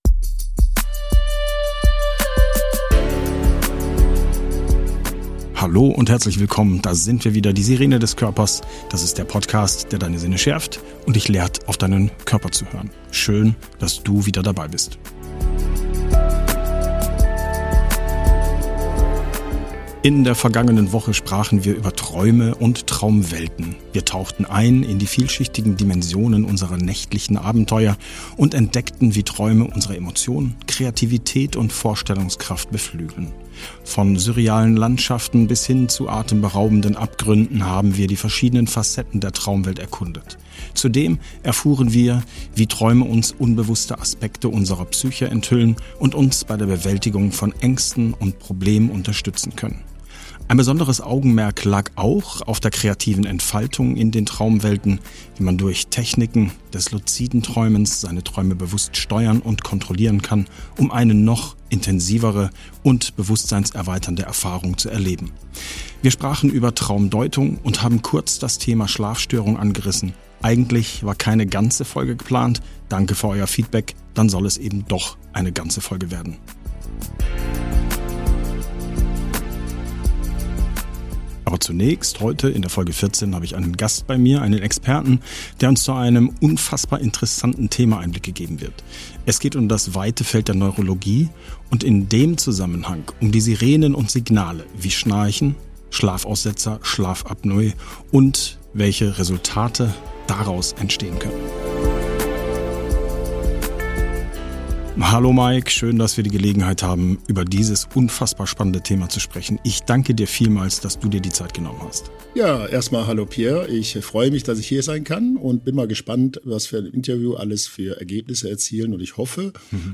In dieser spannenden Episode haben wir einen hochqualifizierten Gast begrüßt - einen fachkundigen Physiotherapeuten mit Spezialisierung auf Neurologie.